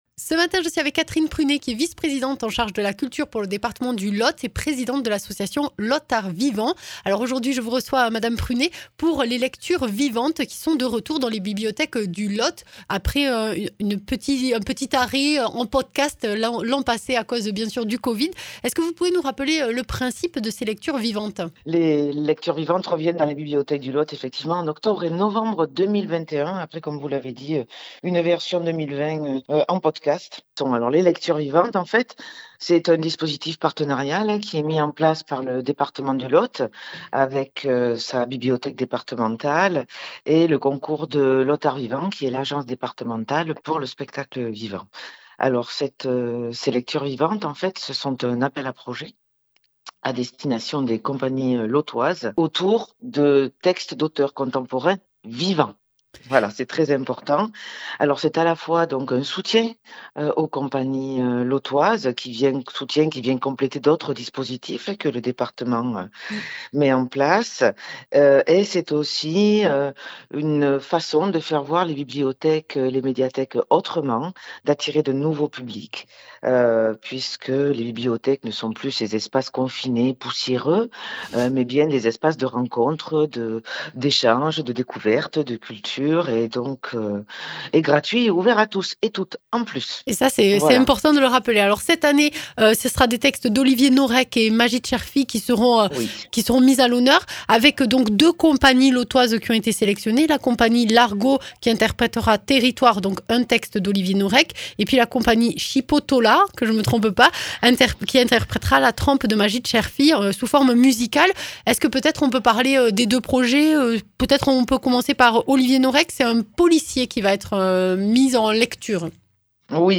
Interviews
Invité(s) : Catherine Prunet, vice-présidente en charge de la culture au département du Lot et présidente de Lot art vivant